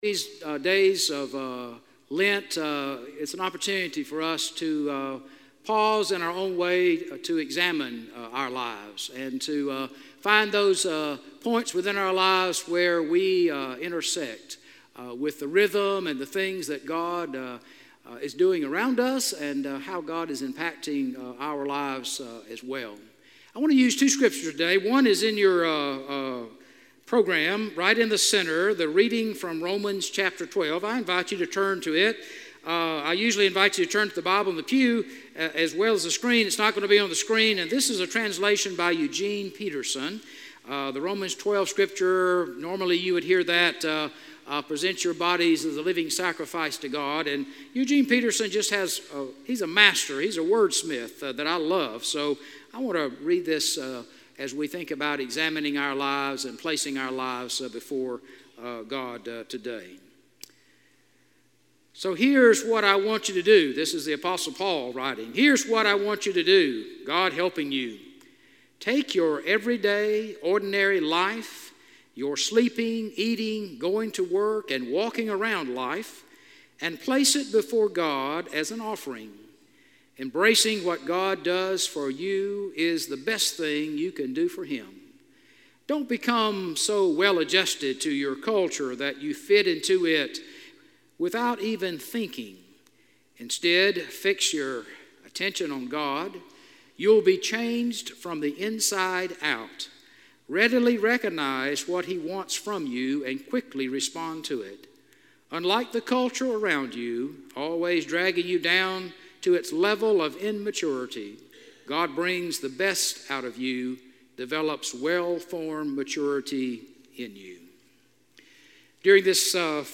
A message from the series "Uncategorized."